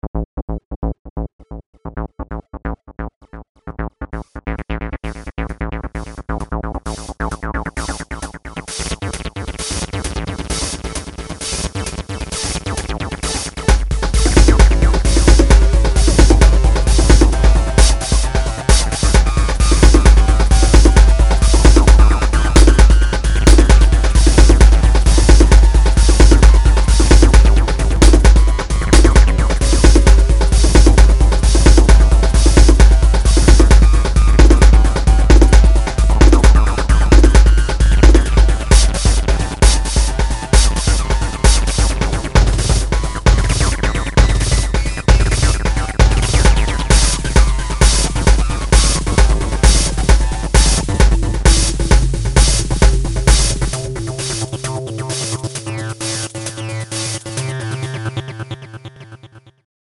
Keyboards, Sequencer, Schlagwerk
schnšrkellose Electronic-Body-Music